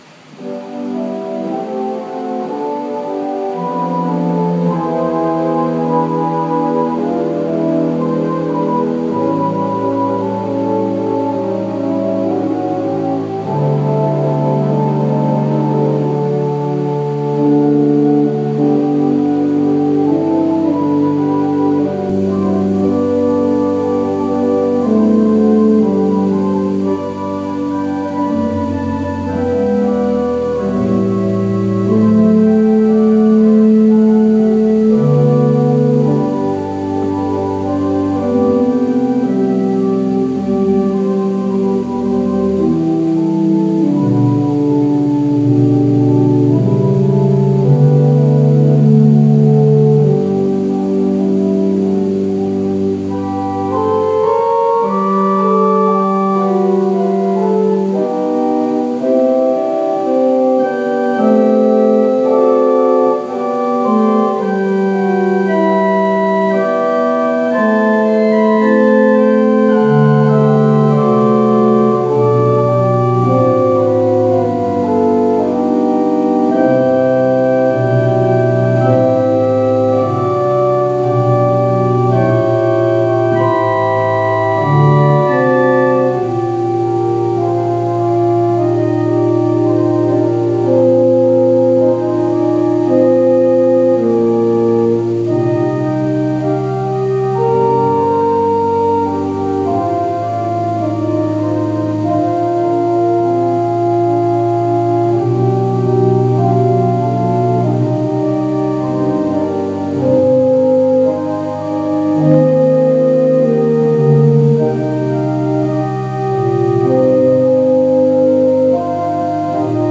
Worship Service October 4, 2020 | First Baptist Church, Malden, Massachusetts
Call to Worship / Invocation / Lord’s Prayer